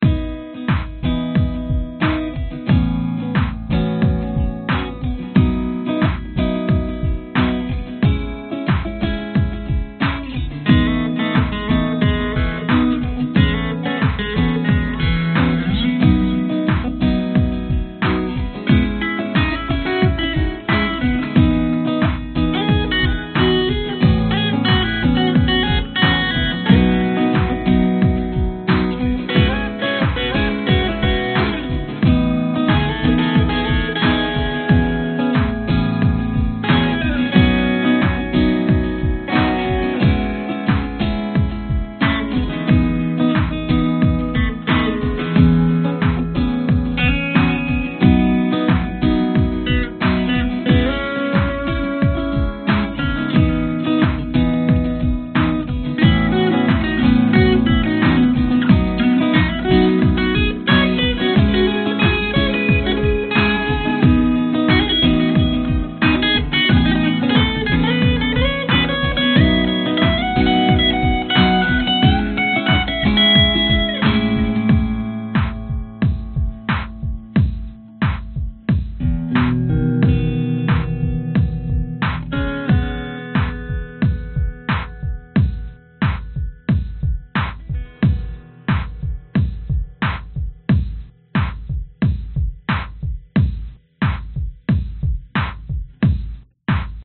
标签： 搞怪 即兴的 跳汰机 吉他
声道立体声